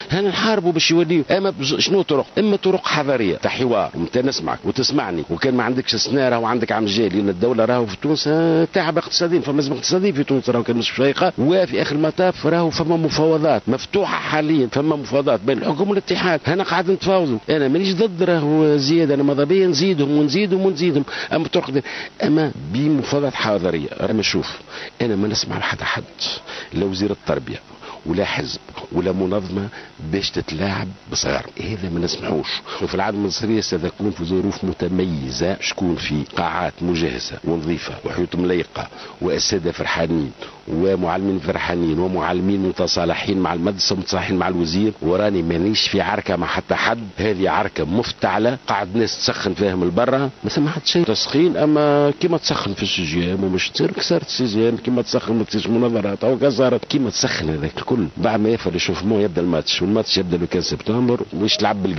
أكد وزير التربية ناجي جلول في تصريح لـ"جوهرة أف أم" اليوم السبت انه لا يعارض زيادة في أجور المدرسين.